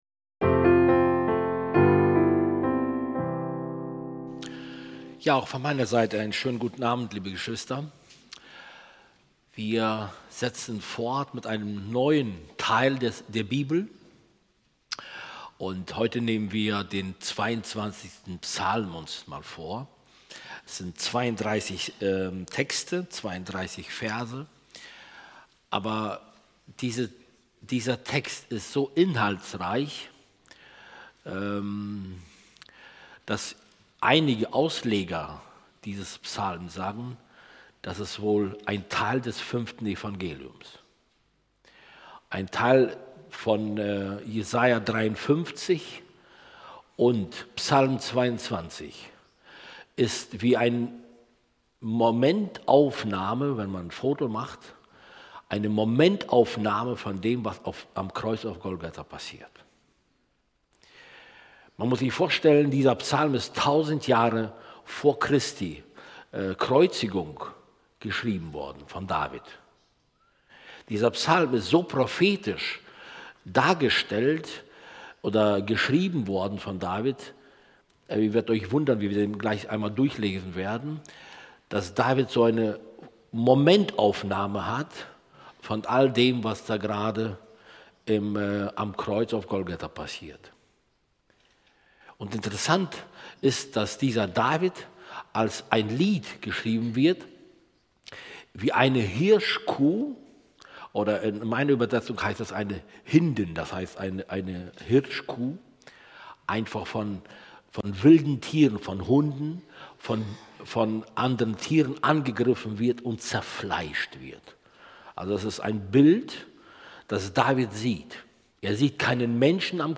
Bibelstunde